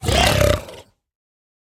Minecraft Version Minecraft Version latest Latest Release | Latest Snapshot latest / assets / minecraft / sounds / mob / piglin_brute / death2.ogg Compare With Compare With Latest Release | Latest Snapshot
death2.ogg